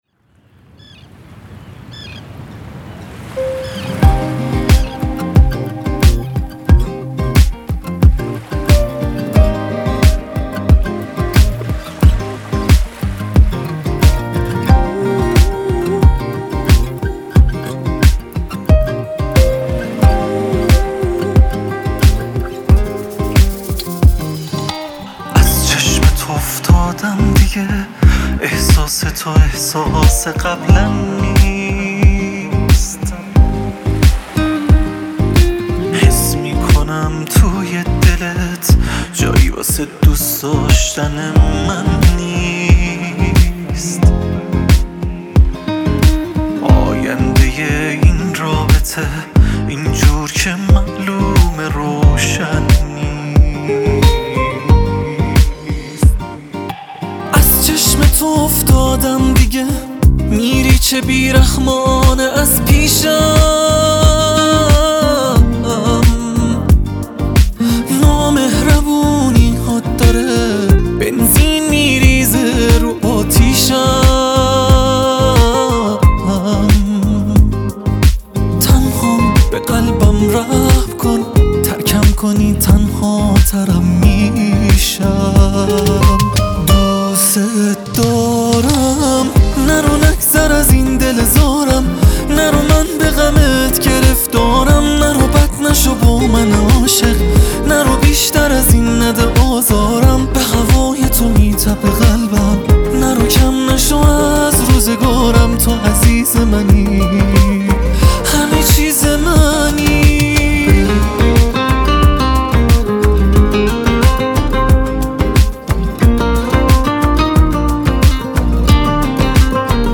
آهنگ غمگین جدید